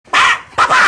papa-fou-rire-nulle-part-ailleursavi-mp3cut.mp3